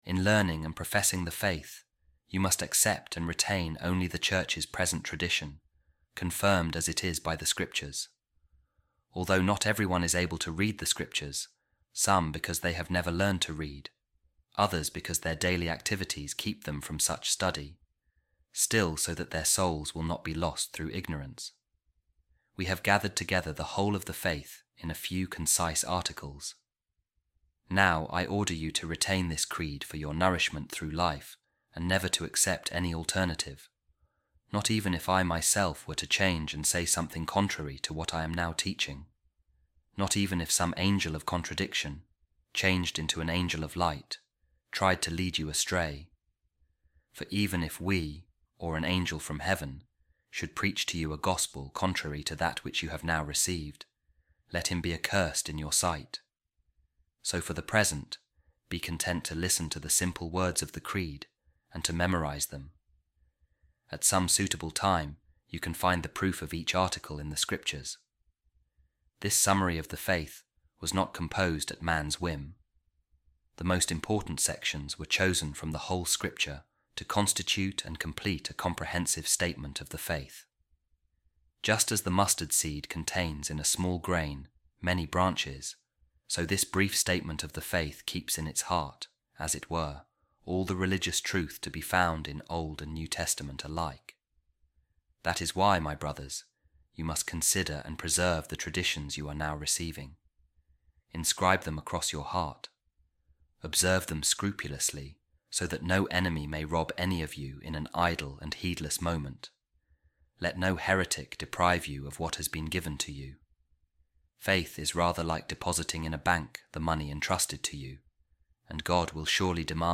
A Reading From The Instructions Of Saint Cyril Of Jerusalem To Catechumens | On The Creed